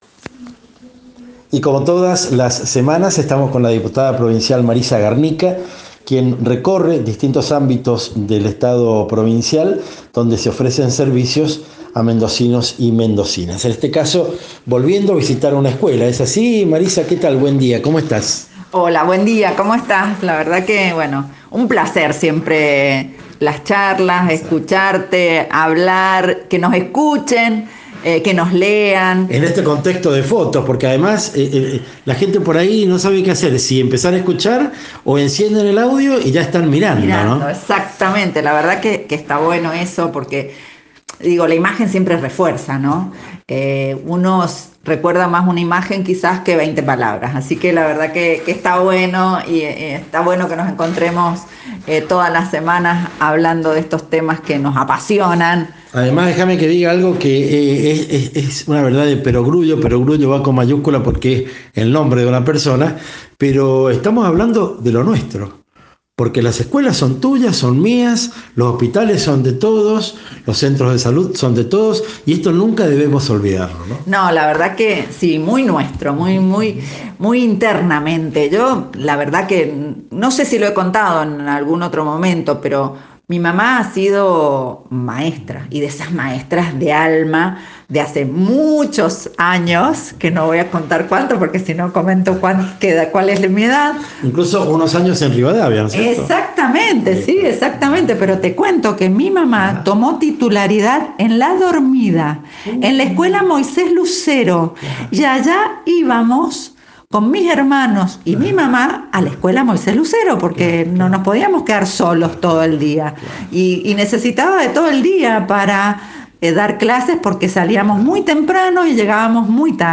Entrevista: Marisa Garnica, Diputada Provincial, 18 de abril de 2023